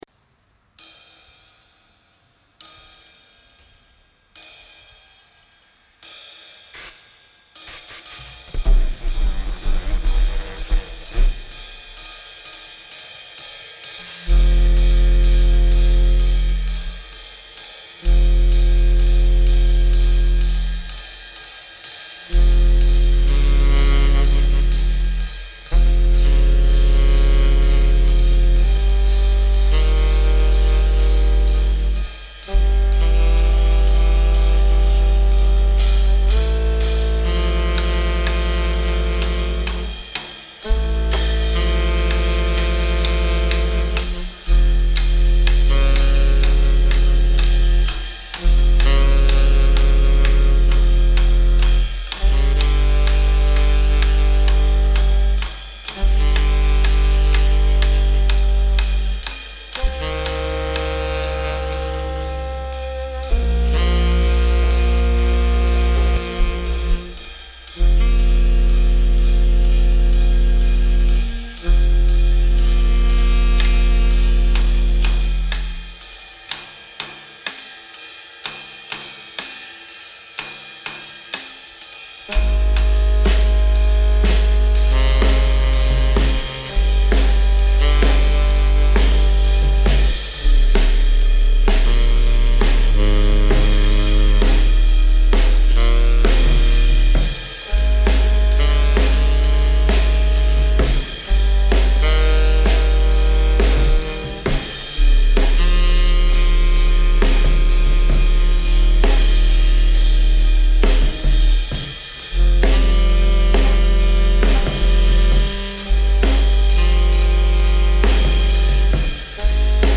Recorded live at the 39th Street loft in Brooklyn.
drums
alto saxophone, fx
tenor saxophone
Stereo (Metric Halo / Pro Tools)